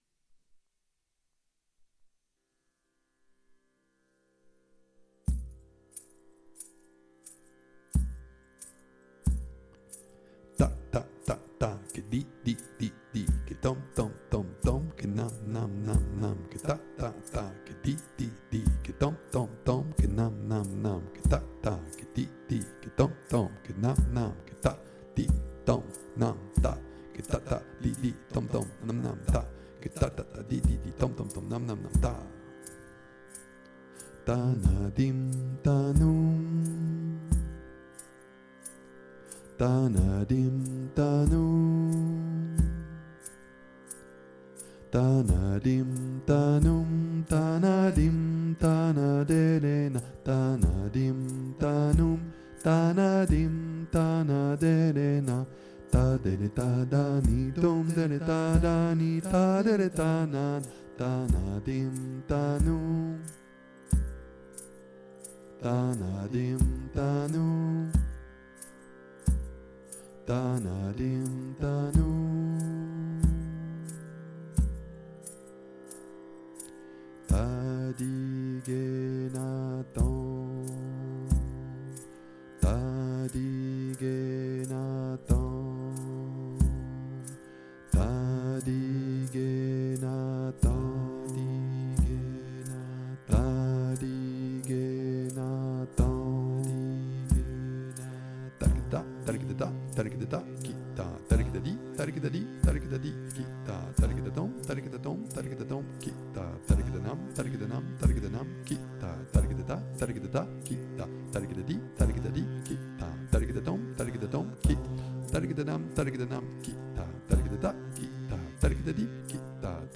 suite_konnakol.mp3